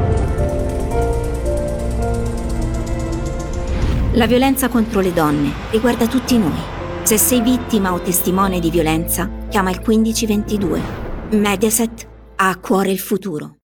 Spot Tv